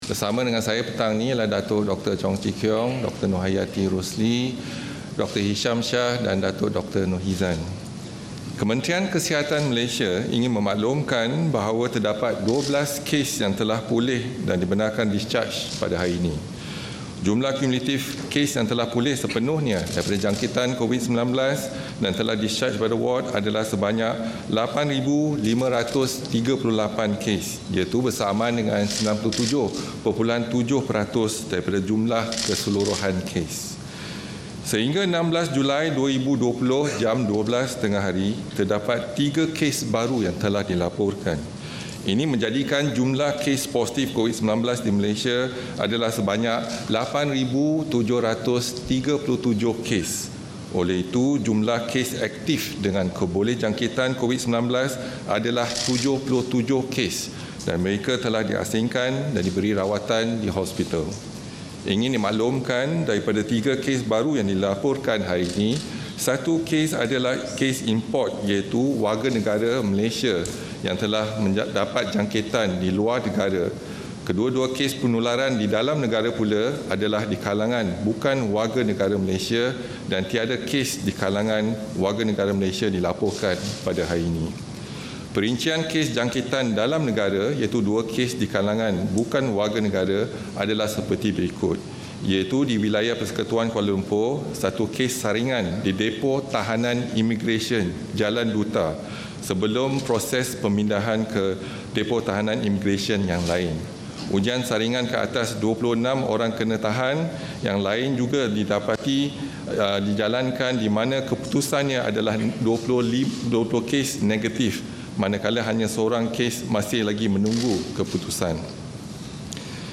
SIDANG MEDIA